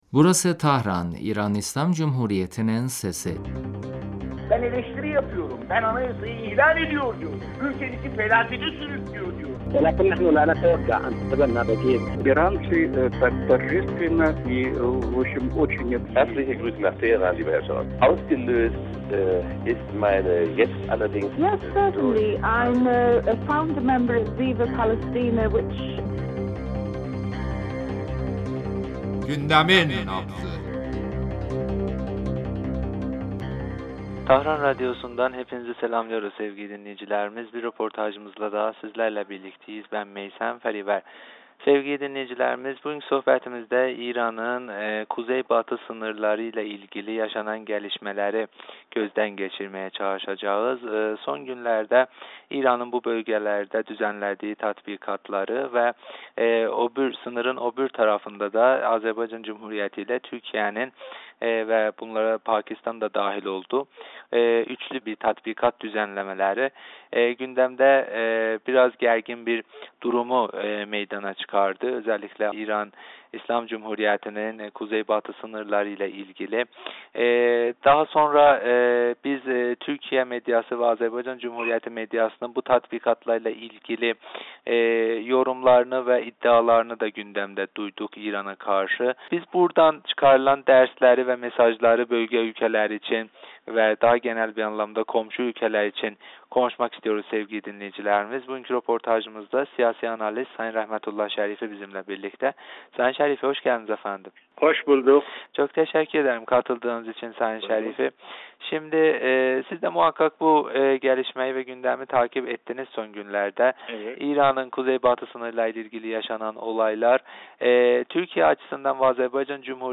radyomuza verdiği demecinde